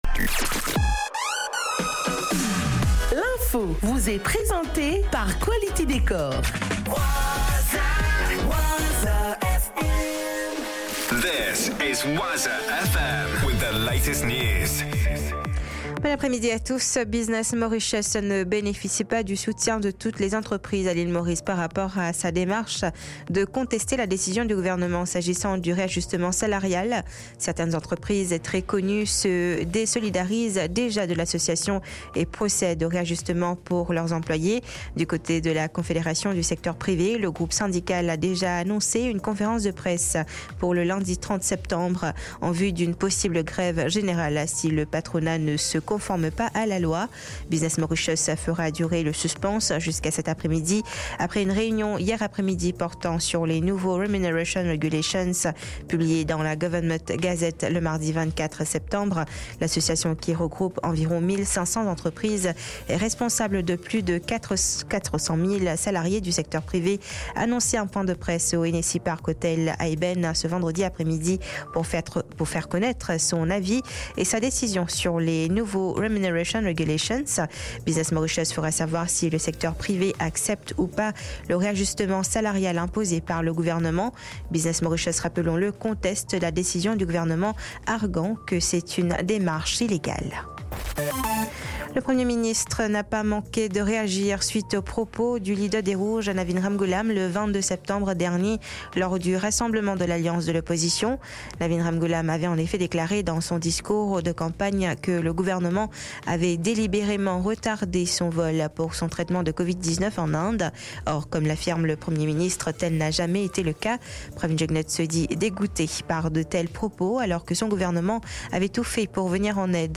News 12hr 23/09/24